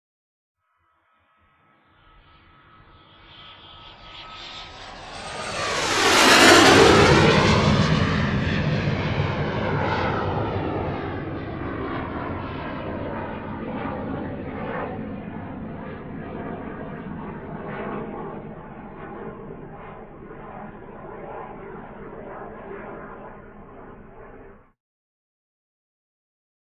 F-5: By Medium; Clear, Bell-like Tone With F-5 Approach, Medium Speed By, Crisp Engine Roar With Away. Medium To Distant Perspective. Jet.